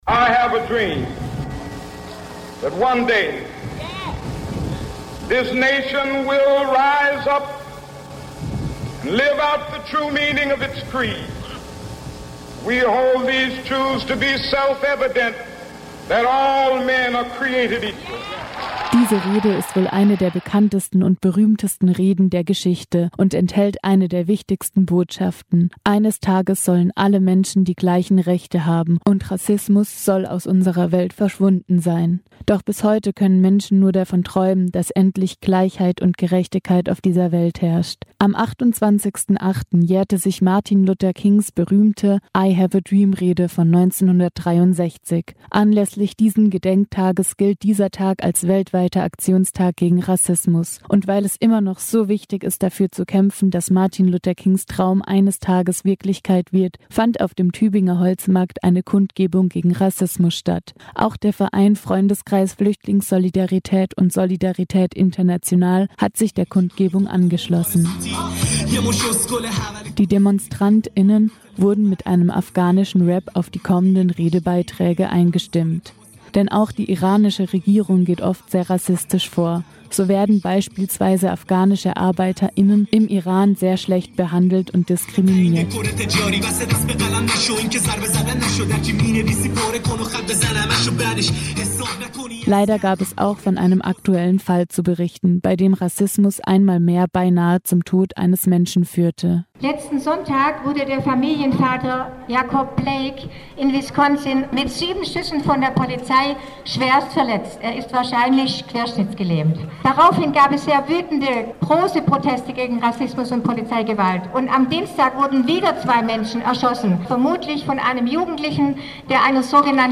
Die Demonstrant*innen wurden mit einem afghanischen Rap auf die kommenden Redebeiträge eingestimmt.
Das Kernstück der Kundgebung war das offene Mikrofon zu dem jede und jeder eingeladen war, sich gegen Rassismus auszusprechen.
Abschließend luden die Veranstalter*innen zum gemeinsamen Singen des italienischen Partisanen Liedes „BellaCiao“ ein.